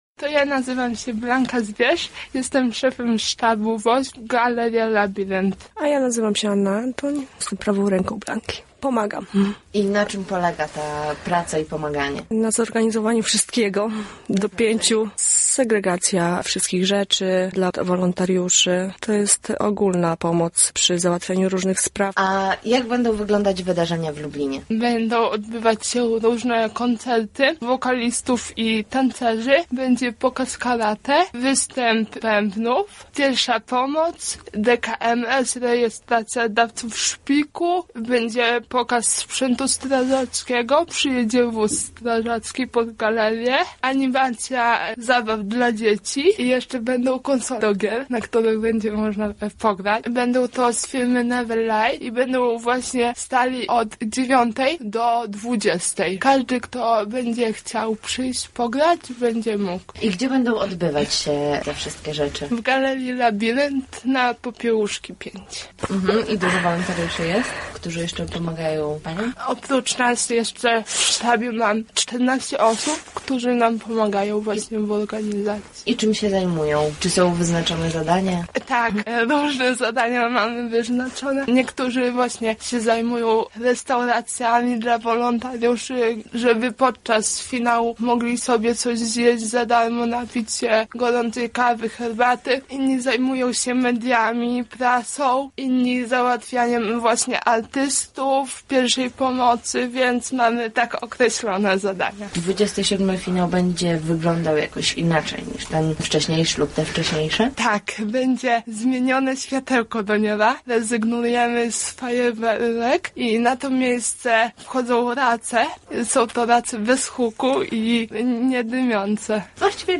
Odwiedziły nas dzisiaj Panie, które przygotowują z tej okazji wydarzenie w Lublinie:
wosp-wywiad.mp3